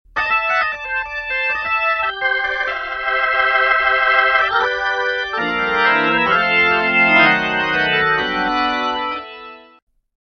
All FAT HEAD SAMPLES ARE RECORDED WITH A  STOCK TRANSFORMER
FAT HEAD Hammond B3: